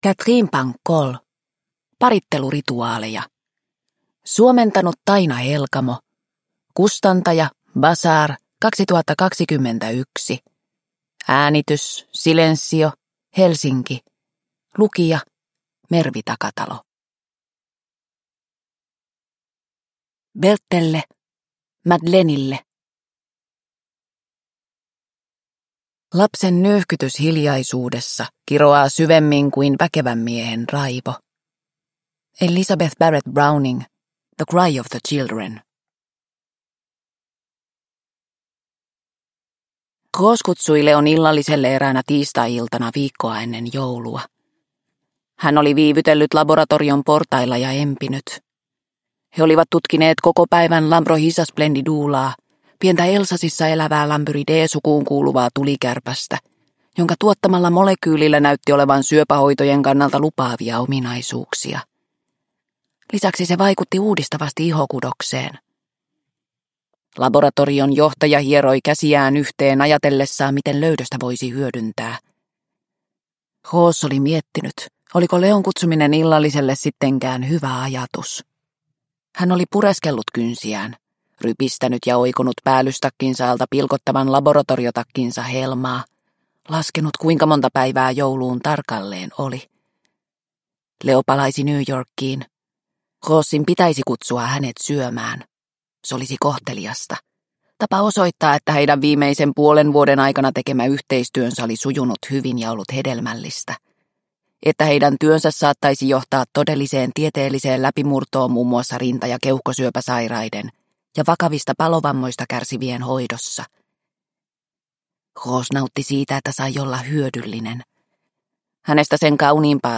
Parittelurituaaleja – Ljudbok – Laddas ner